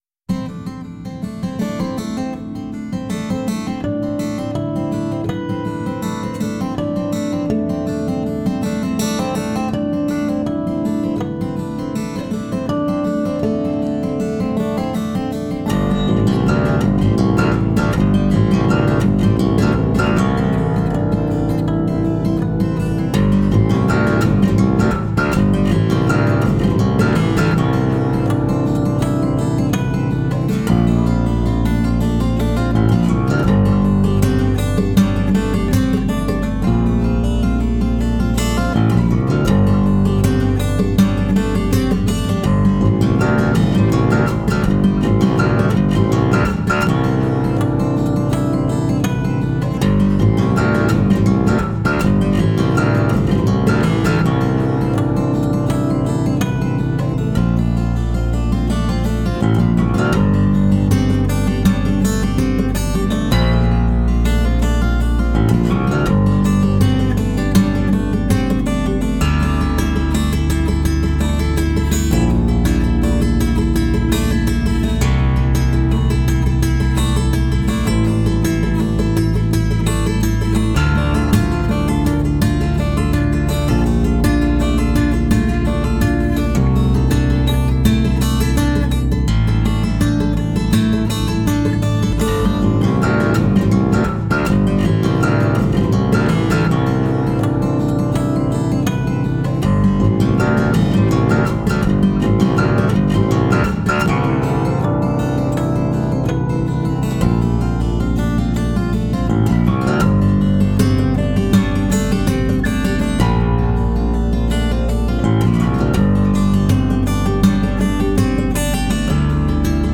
solo harp guitar instrumental